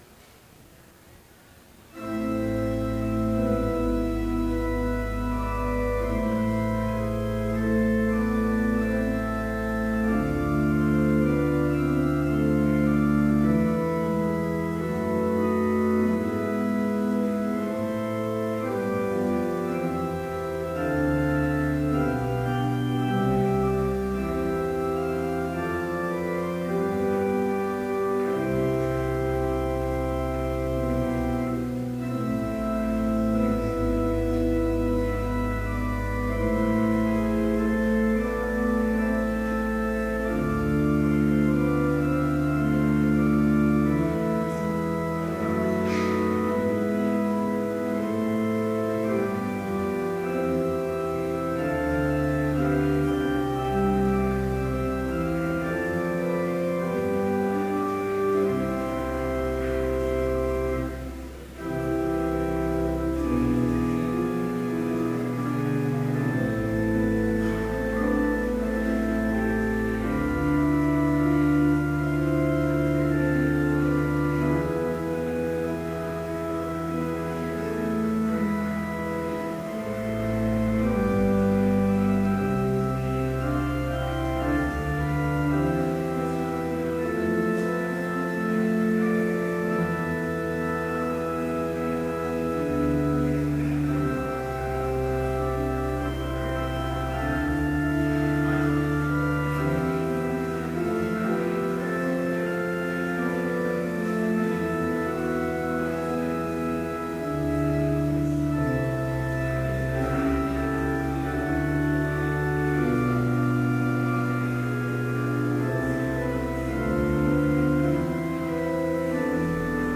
Complete service audio for Chapel - March 26, 2013